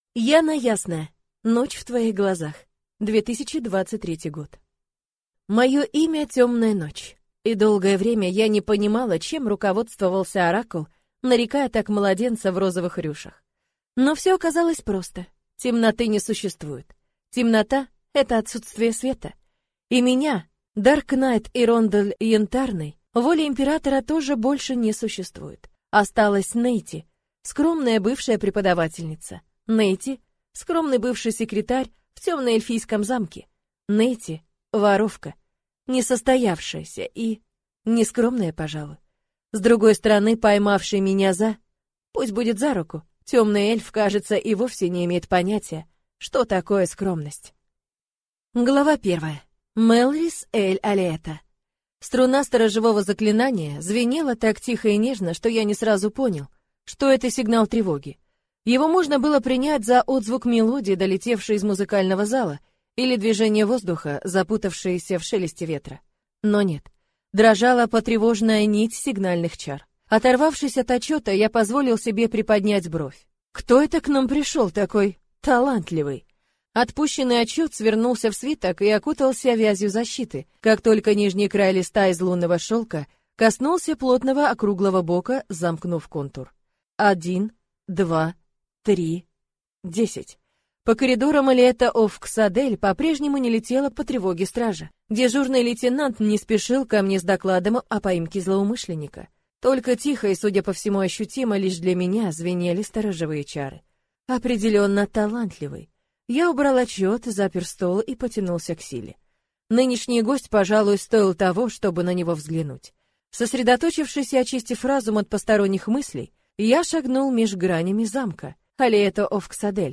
Аудиокнига Ночь в твоих глазах | Библиотека аудиокниг